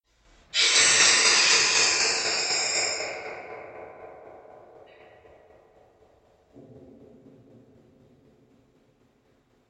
Звуковой эффект, похожий на паранормальное явление
zvukovoj_effekt_pohozhij_na_paranormalnoe_yavlenie_khw.mp3